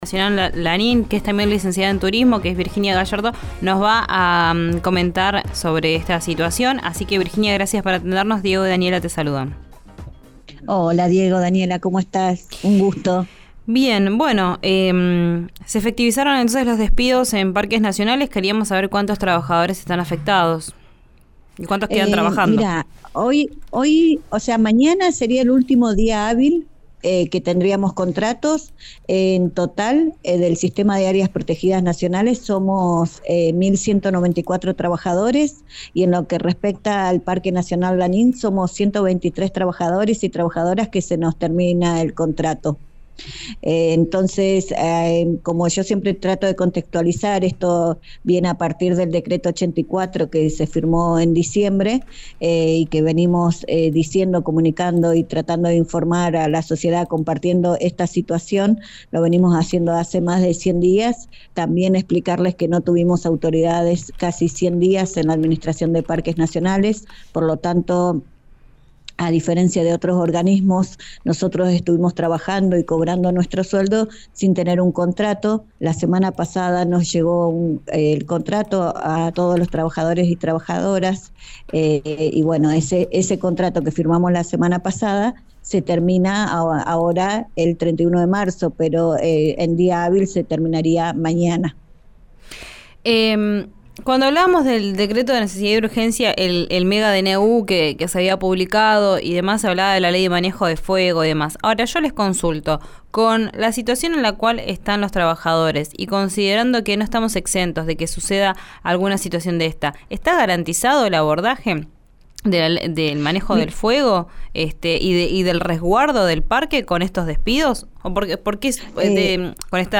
«Somos contratados. Se vencen el 31 de marzo. Todavía no sabemos a cuántos les van a renovar o no», dijo este martes, en diálogo con «Vos al aire» en RÍO NEGRO RADIO